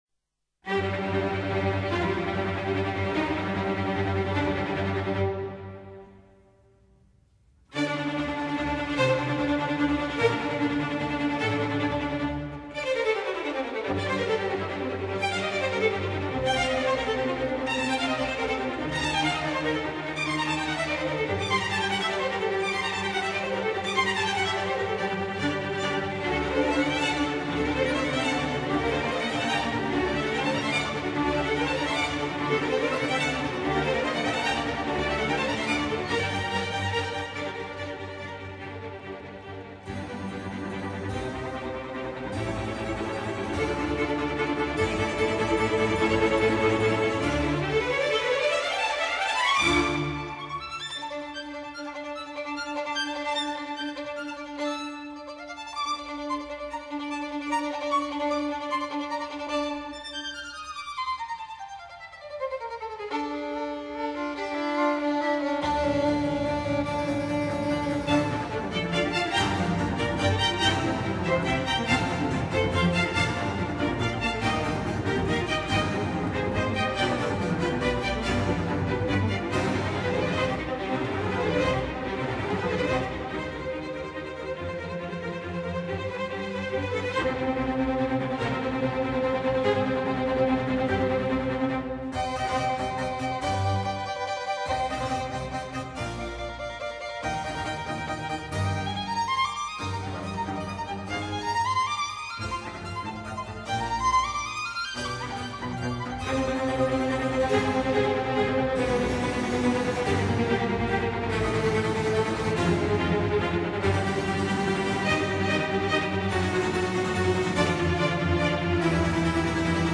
G小调，作品第8号，第二首
III．Presto：
第三乐章：夏日雷阵雨，急板
第一和第二小提琴飞速进行的音阶一个紧接一个，是闪电在乐队中放射：
低音提琴和大提琴则是隆隆的雷声。